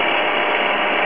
* Changed the flps.wav file so it can be looped.
flaps.wav